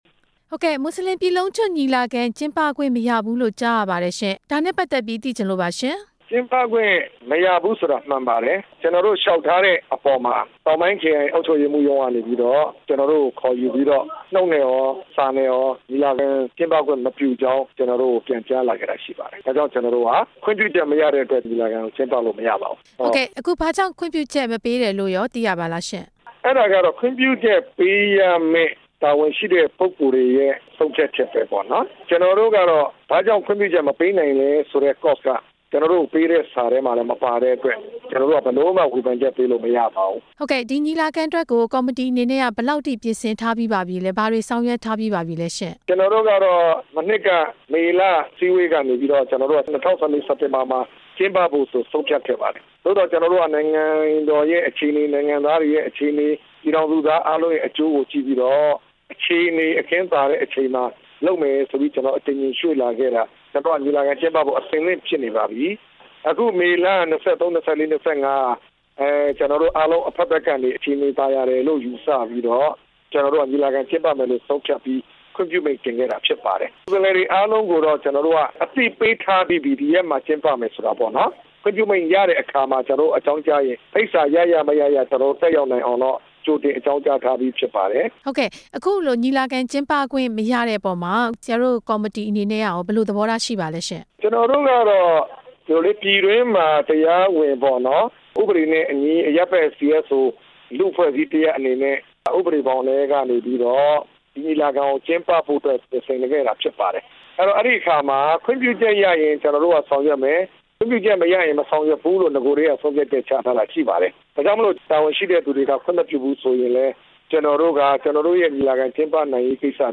မွတ်စလင်များ ပြည်လုံးကျွတ် ညီလာခံအကြောင်း မေးမြန်းချက်